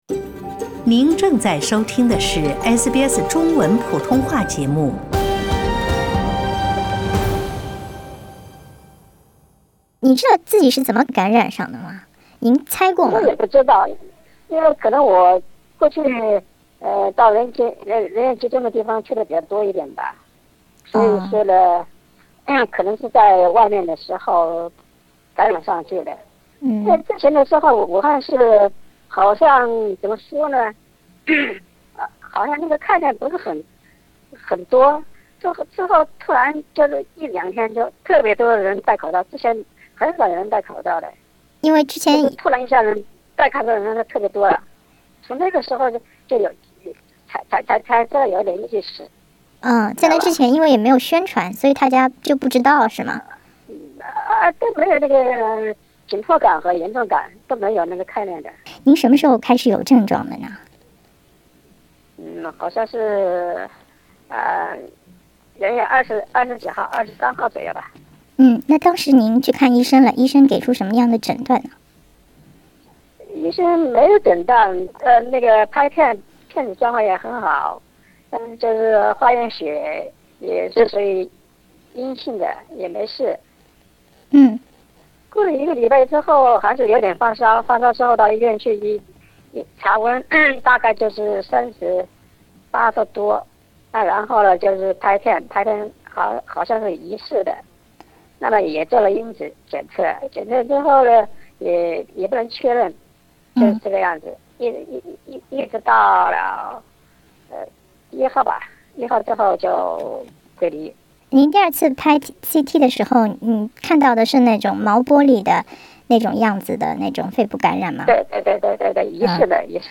独家采访：一位武汉疑似感染者的17天
我们也对他的采访声音做了变音处理。